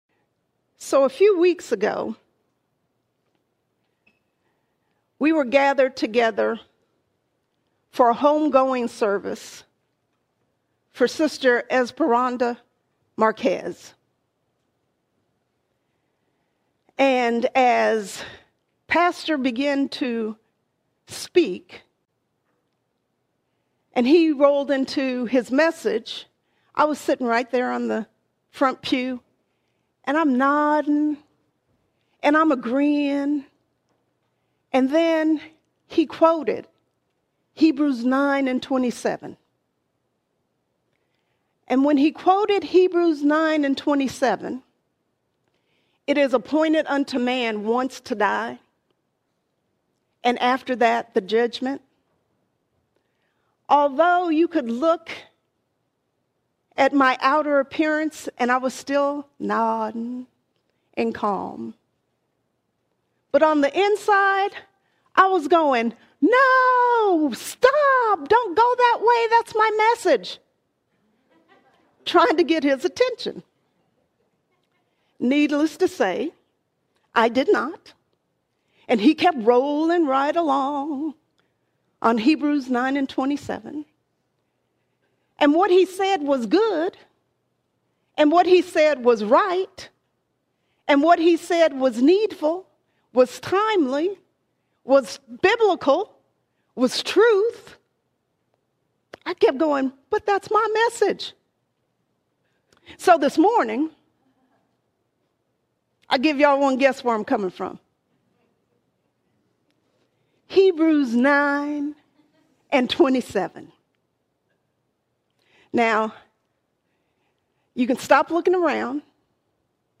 19 January 2026 Series: Sunday Sermons All Sermons What's Next What’s Next We've been saved to live with eternity in view, shaping daily choices around what comes next.